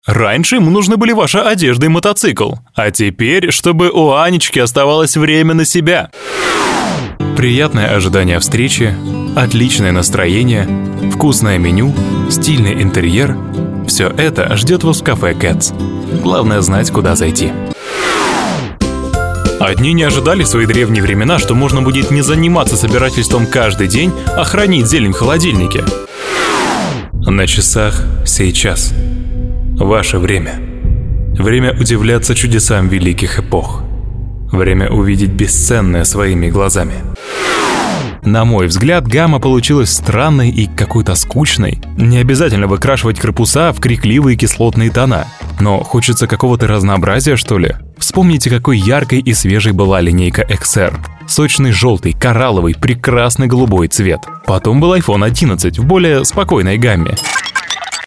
Тон кабинка/ TLM 103 / RME babyface pro.